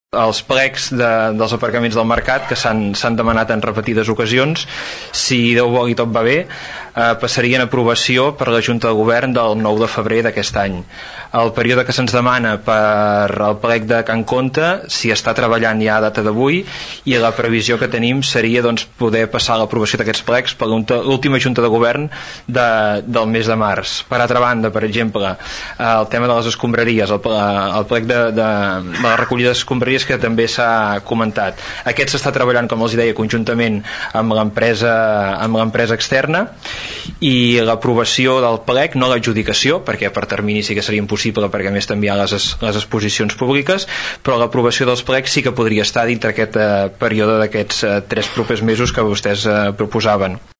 El regidor d’Hisenda, Marçal Vilajeliu, va mostrar el compromís de tot l’equip de govern amb la moció i va avançar algunes acciones concretes que s’han de fer efectives ben aviat.